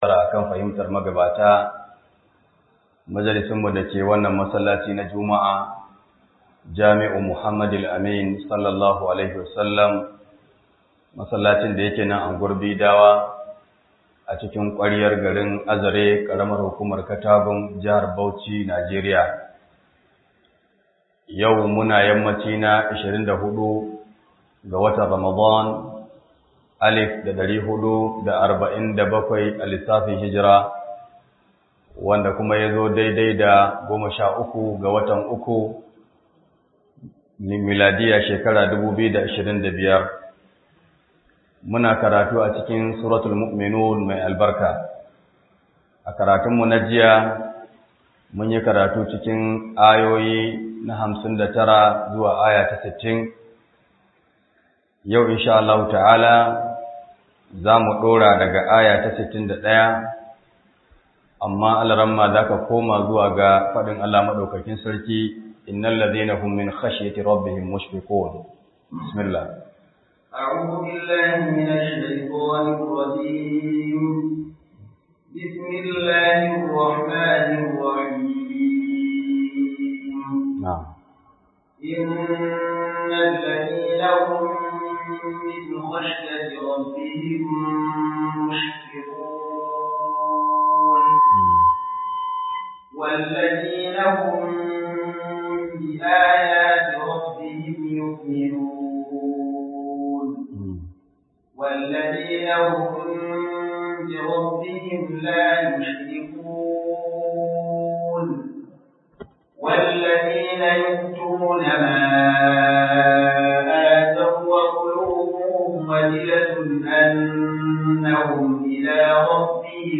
Scholar